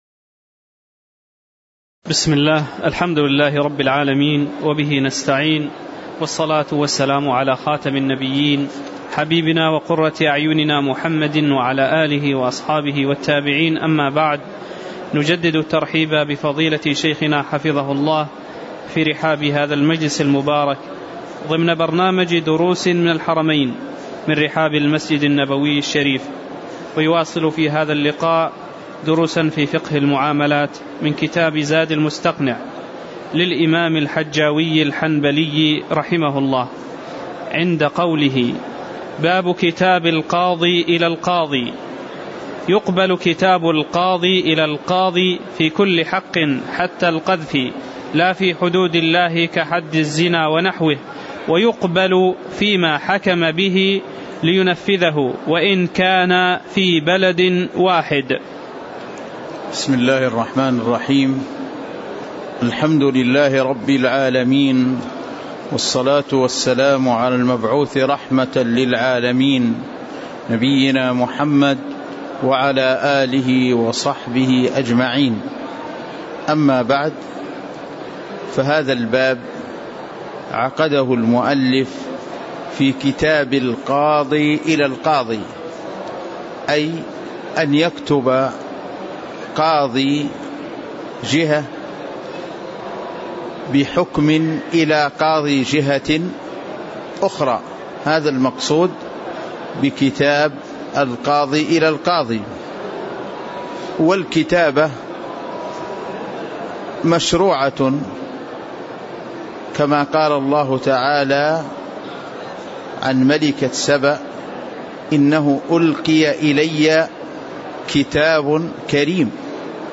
تاريخ النشر ١٤ رجب ١٤٣٨ هـ المكان: المسجد النبوي الشيخ